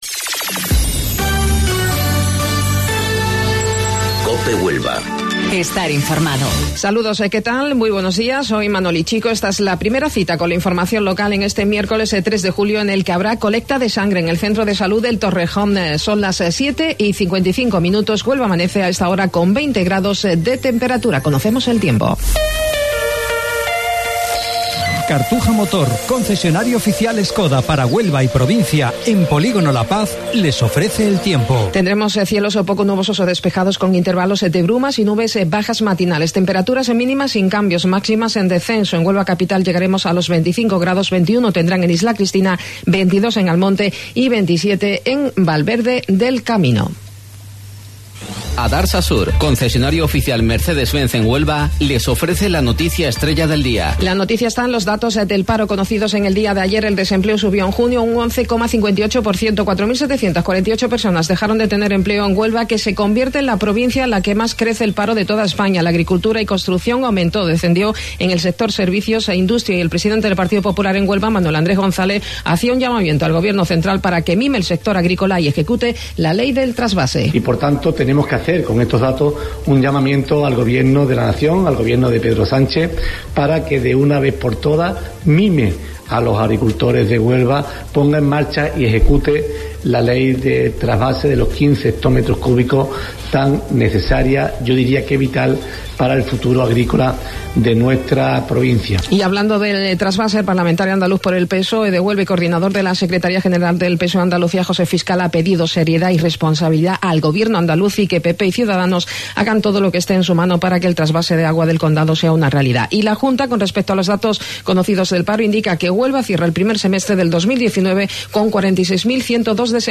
AUDIO: Informativo Local 07:55 del 3 de Julio